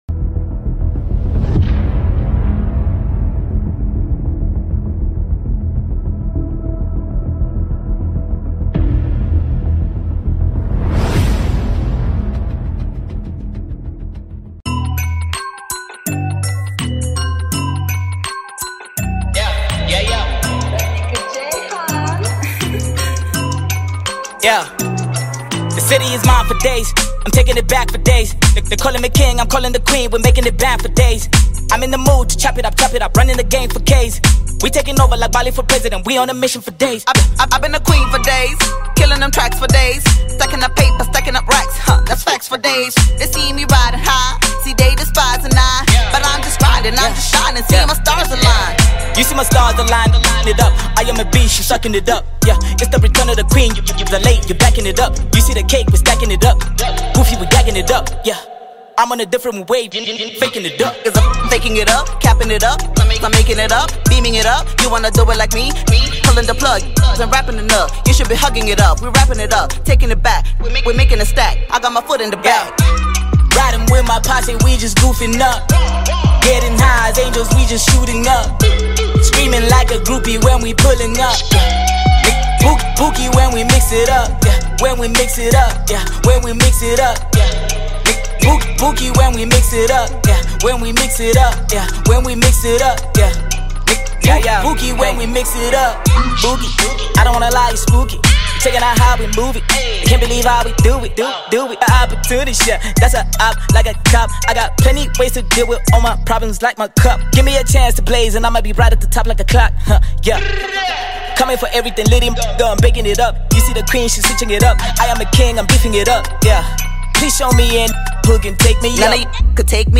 Here comes a Euphoric Mesmerizing soundtrack named
a Zambian-born hip-hop recording artist